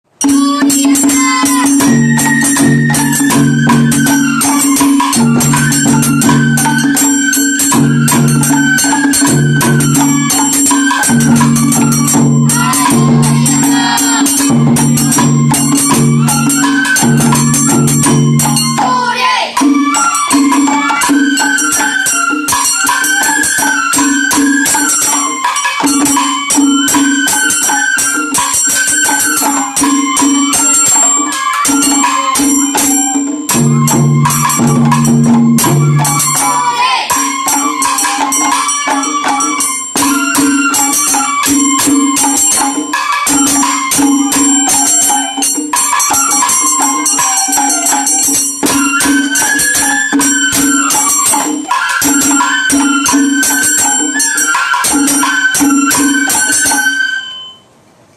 子どもたちが奏でる佐原囃子
佐原囃子